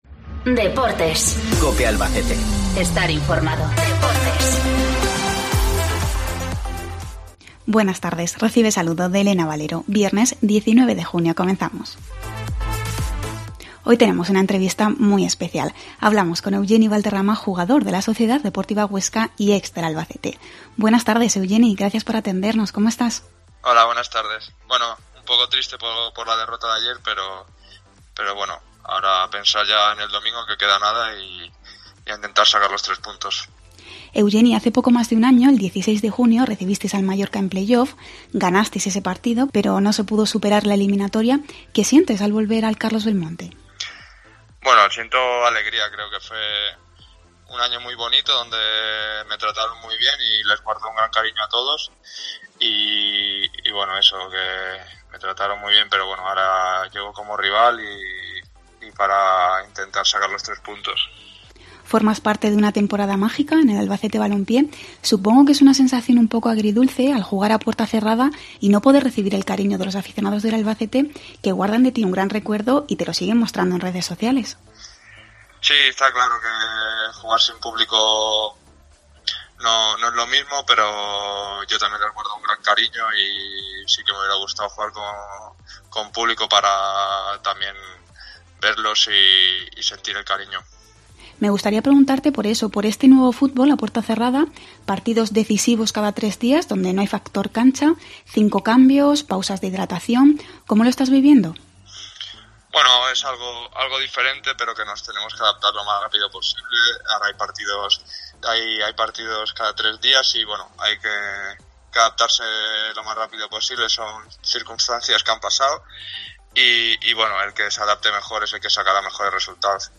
Entrevista Deportes